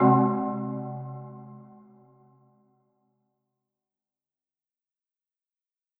Category: Percussion Hits
Marimba-Hit-2.wav